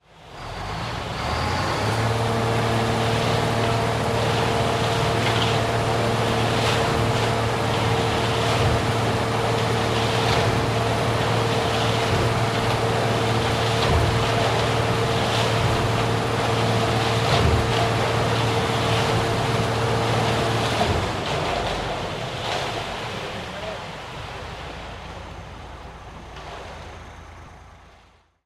Качество записей проверено – никаких лишних шумов, только чистый звук техники.
Звук быстрого вращения барабана перед заливкой бетона